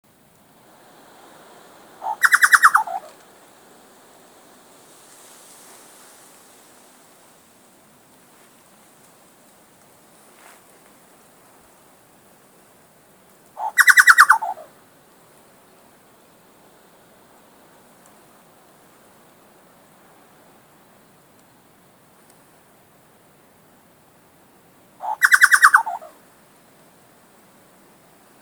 Nome científico: Scelorchilus rubecula
Nome em Inglês: Chucao Tapaculo
Detalhada localização: Cascada de Tocoihue
Condição: Selvagem
Certeza: Fotografado, Gravado Vocal